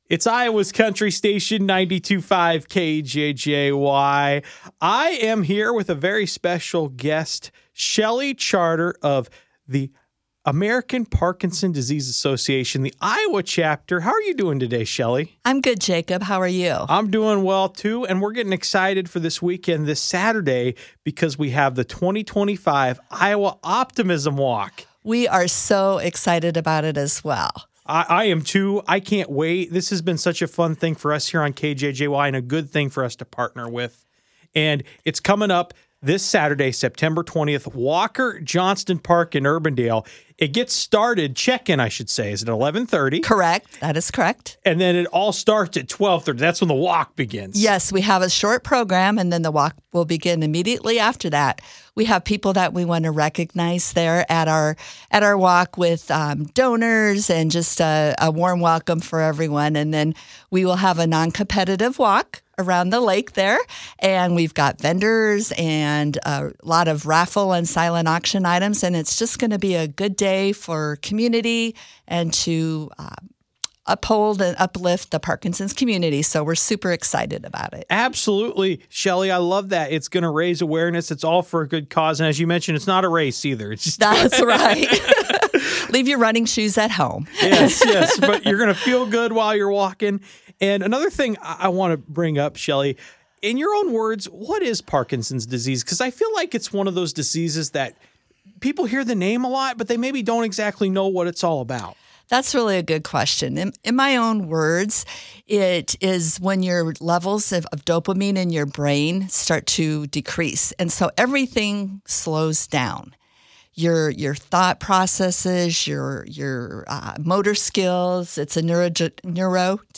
iowa-optimism-walk-2025-interview-for-web.mp3